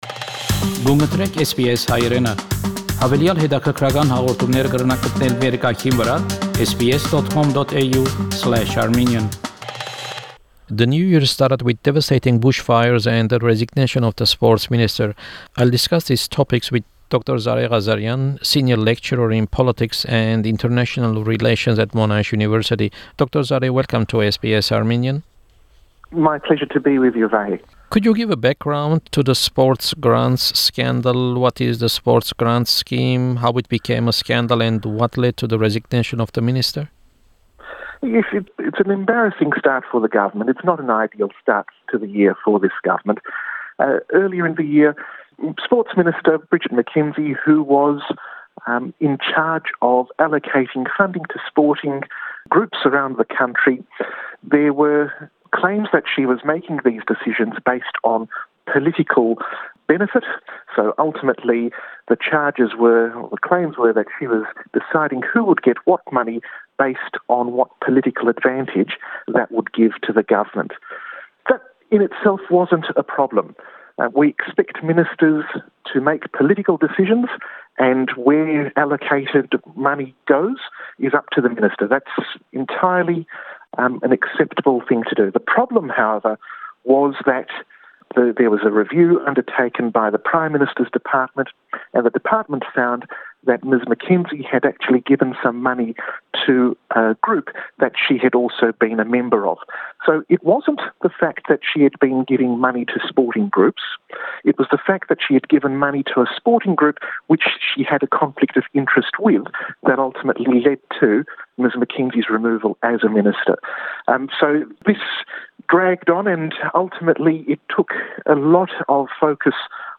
The main topic of the interview is current Australian politics.